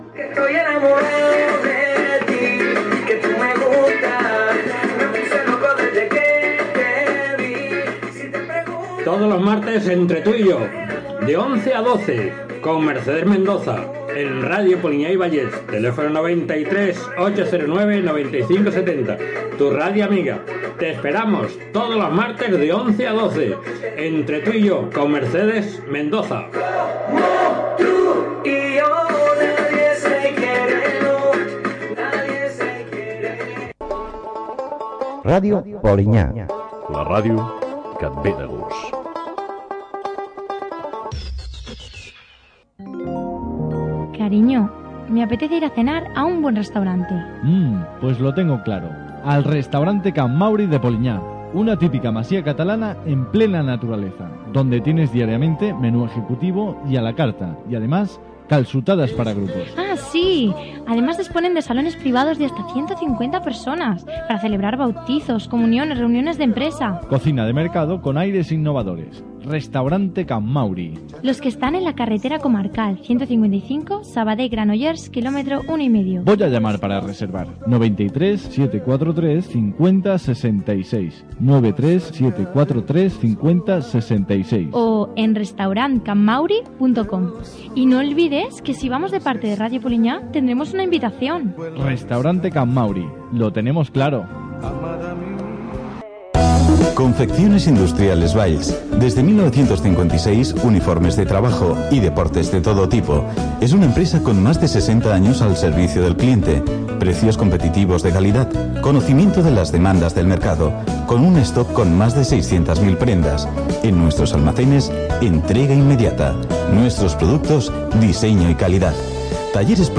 Promoció d'"Entre tu y yo", indicatiu de l'emissora, publicitat, indicatiu.